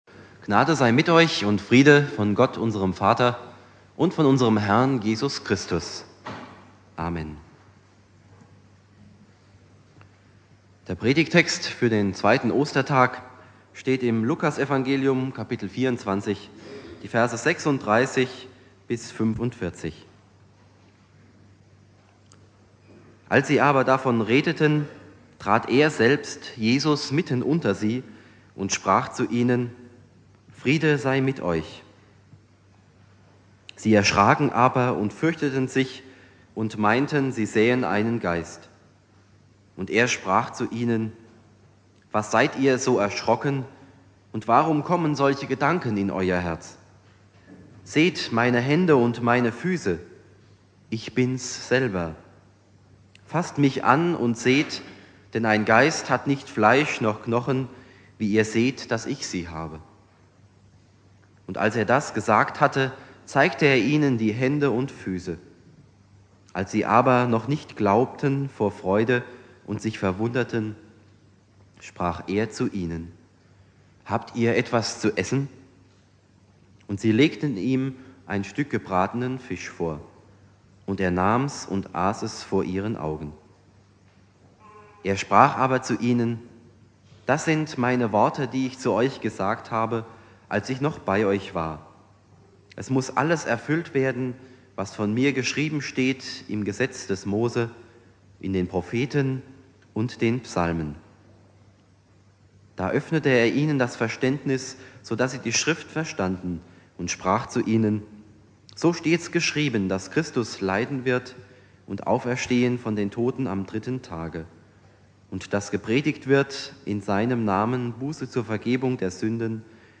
Predigt
Ostermontag